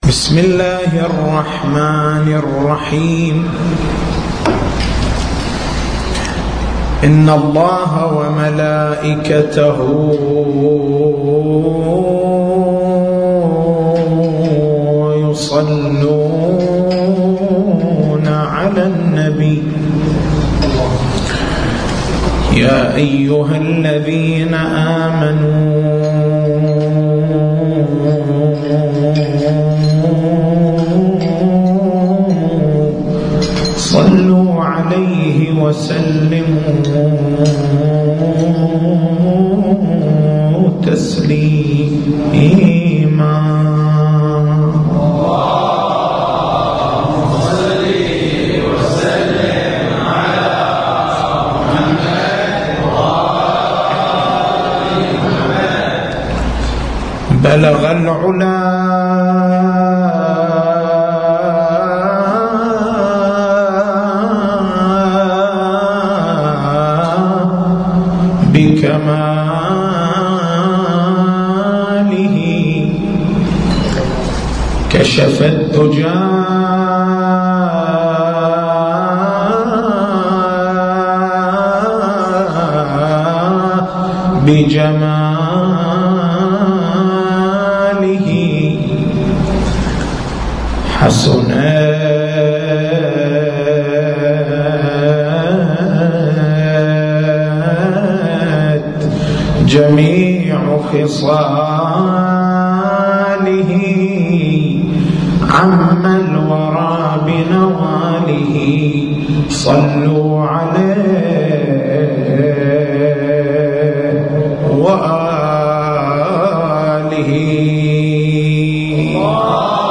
تاريخ المحاضرة: 16/09/1434 نقاط البحث: ما هو معنى الحجية؟